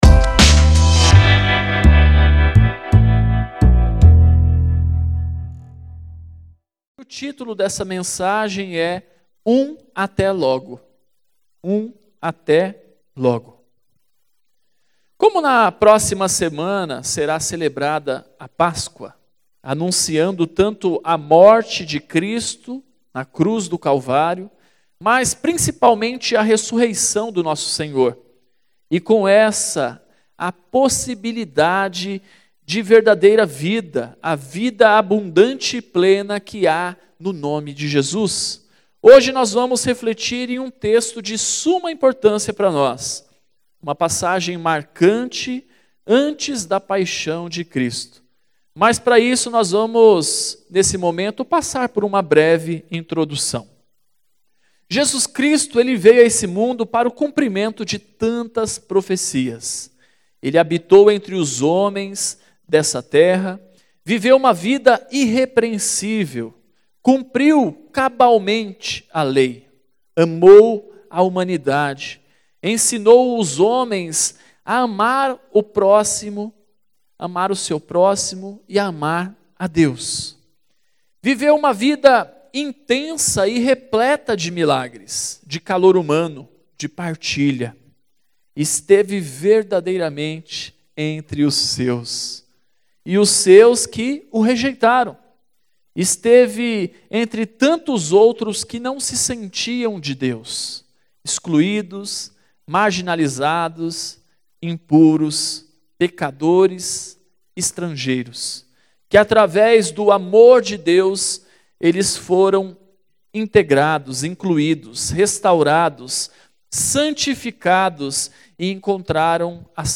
Mensagens